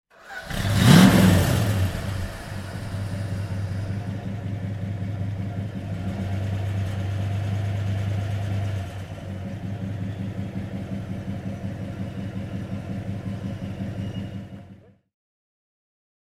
Engine sounds of Plymouth Barracuda vehicles (random selection)